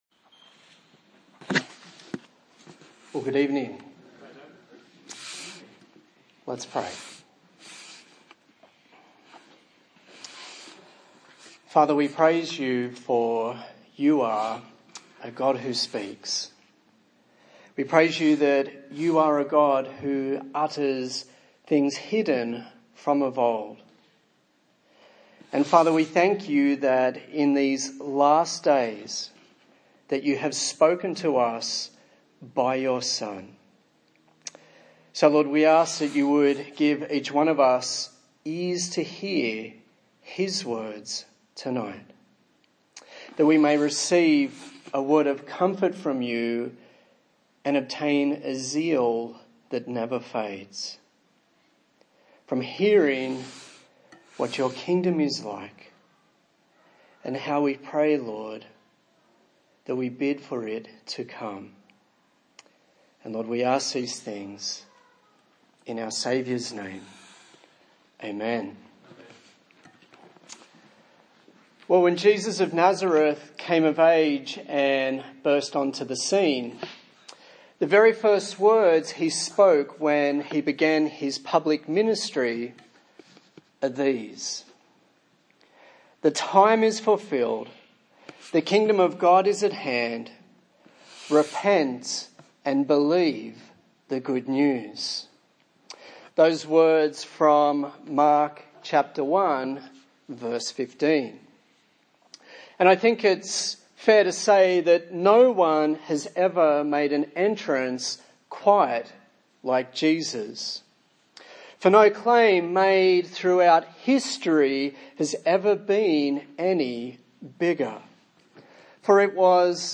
A sermon in the series on the book of Matthew